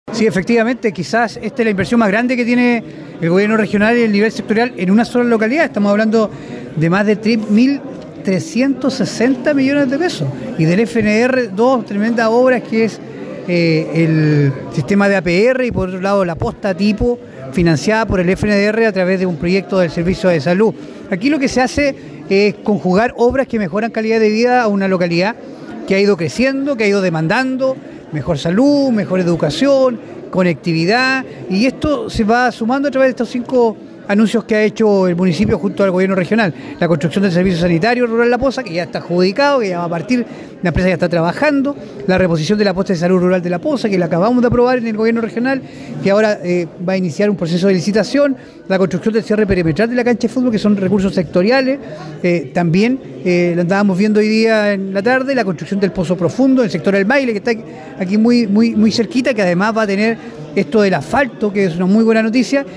El Consejero Francisco Reyes señaló que son obras muy importantes, que mejoran la calidad de vida de los vecinos y vecinas del sector La Poza.